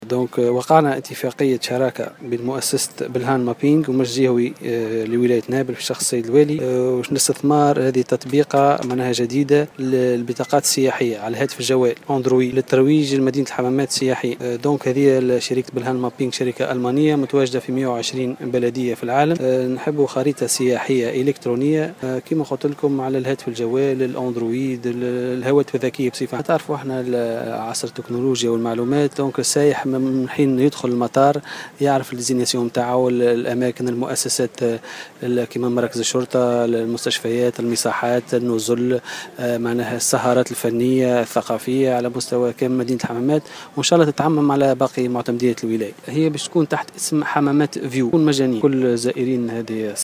أكد حسام الصغير معتمد ورئيس النيابة الخصوصية لبلدية الحمامات في تصريح لمراسلة الجوهرة "اف ام" اليوم الثلاثاء 2 أوت 2016 أن المجلس الجهوي لولاية نابل وقع اتفاقية شراكة مع مؤسسة "بلهان مابيننغ" وهي مؤسسة ألمانية تنشط في 120 بلدية في العالم لإطلاق تطبيقة جديدة للسياح .